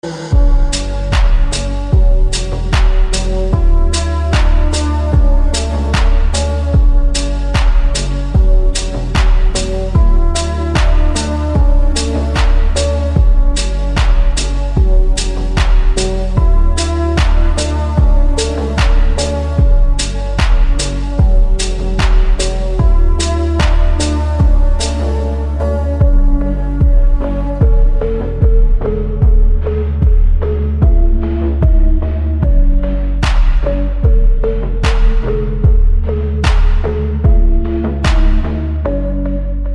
Category: Classical